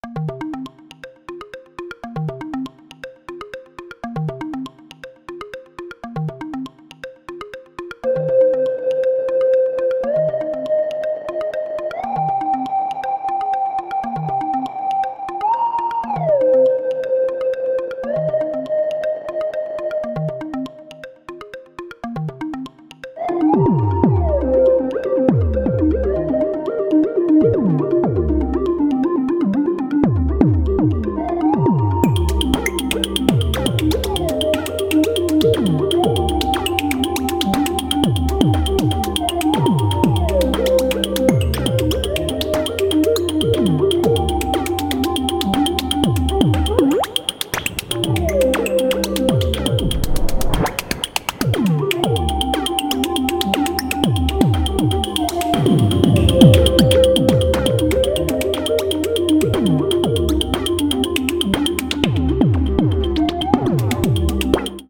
All the sounds are made with the comb filter and the factory noise sample “grainy”:
It has a very “Metroid Prime Soundtrack” feel to it, which I am here for.